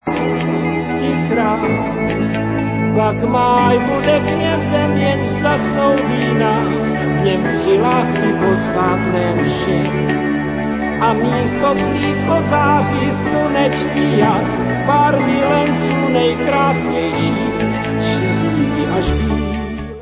české pop-music